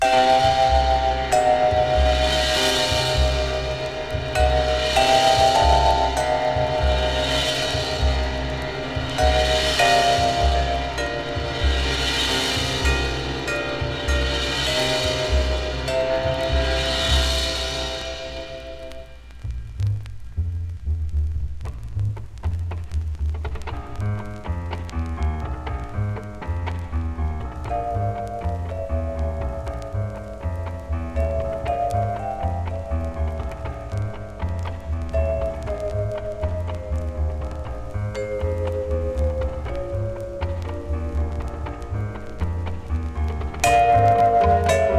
ジャケ裏写真のアルミニウム・ドーム録音で、スモールコンボエキゾな深く広がりあるサウンドは魅力的。